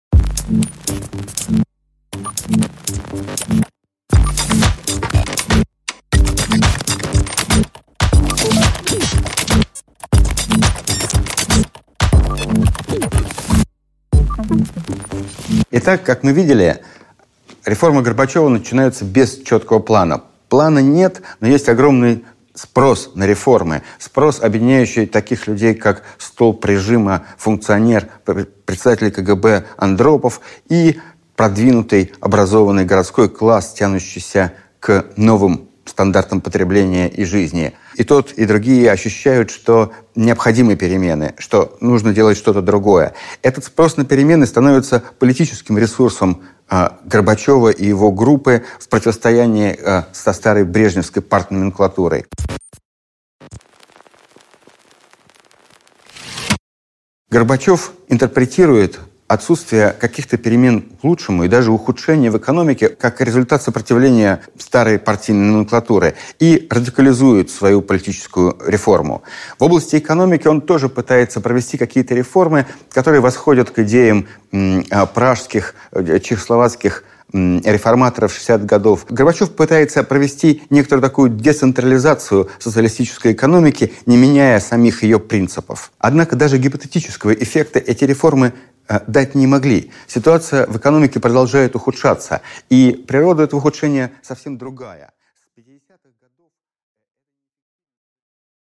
Аудиокнига Революция снизу: расколы и трещины советской системы | Библиотека аудиокниг